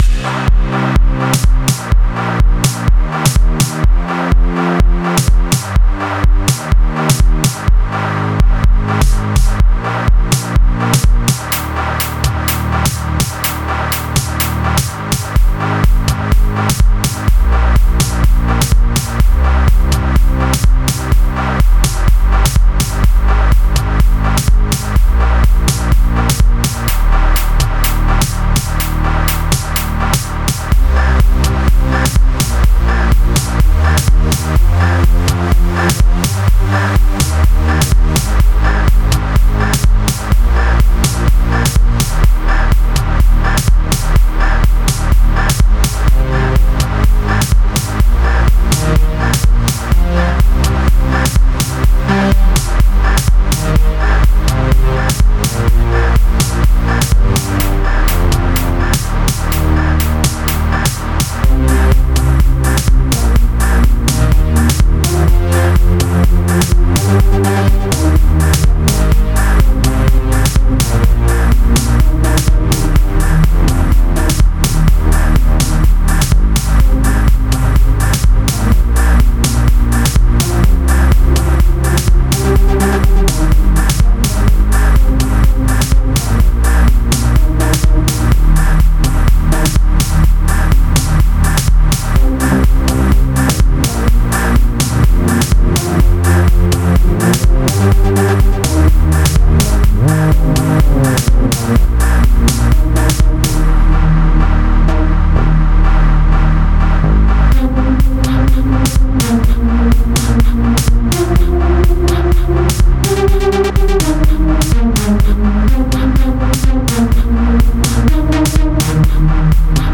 Gqom Size